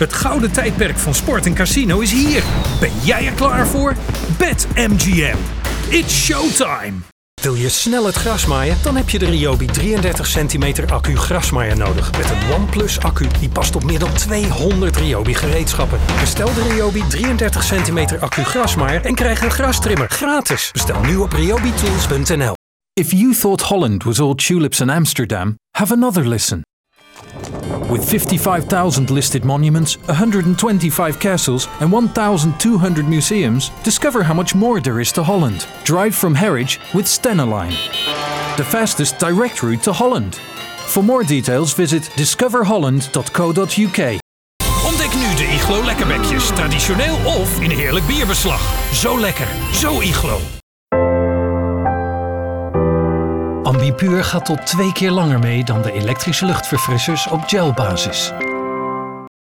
Dutch - Male
Voice Reel 2024 (Short)
Commercial, Bright, Upbeat, Conversational